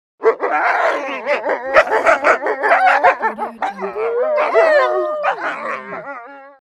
Wolf Bark Bouton sonore
Play and download the Wolf Bark sound effect buttons instantly!